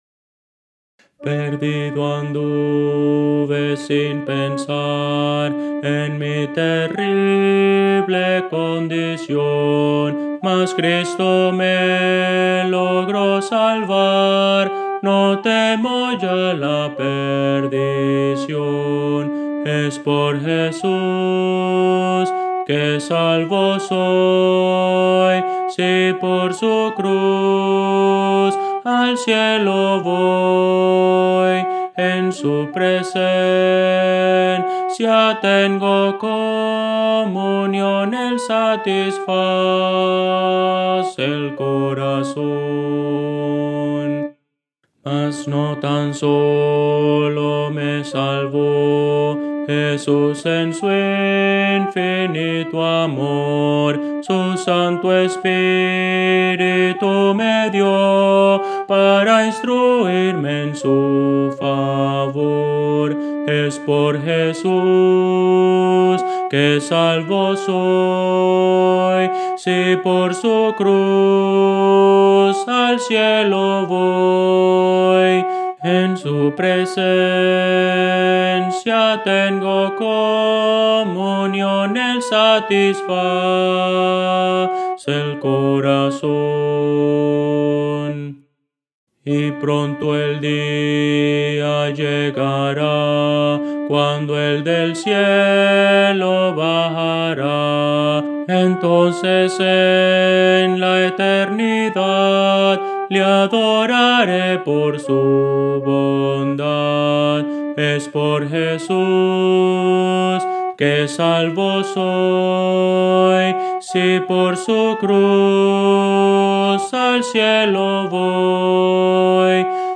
A capela – 4 Voces
Voces para coro
Contralto – Descargar